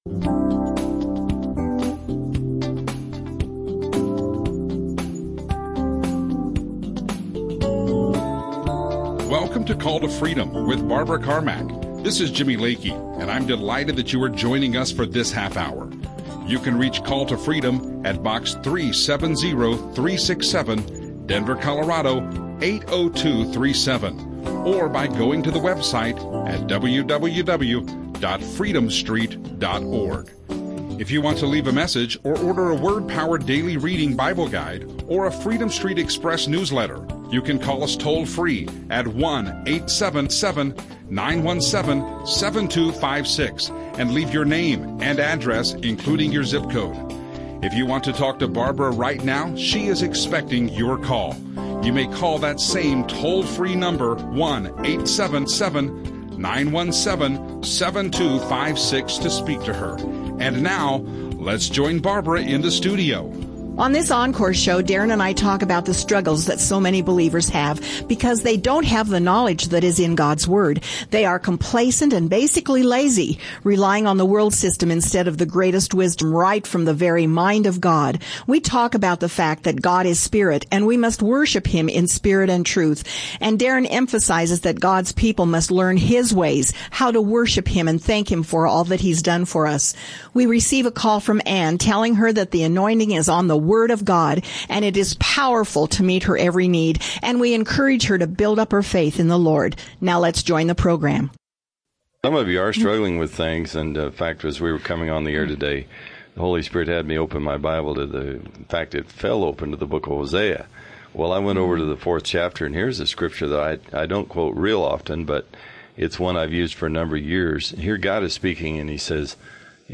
GOD has given us everything we need in the Spirit + phone calls